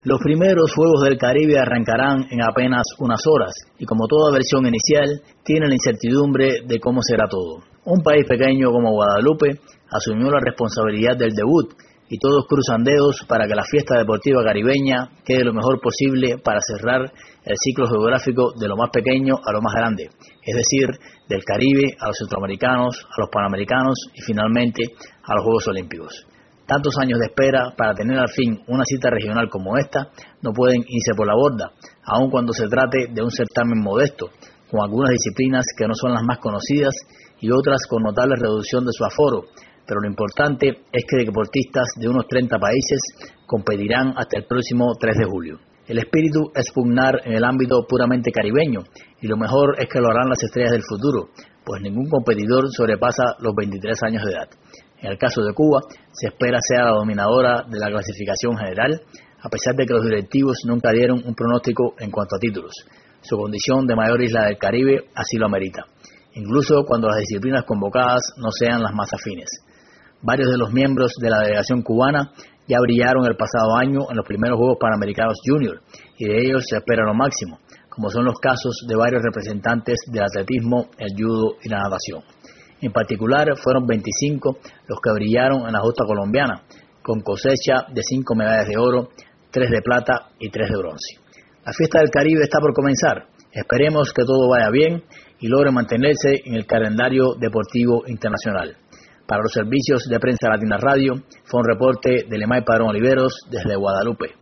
desde Basse Terre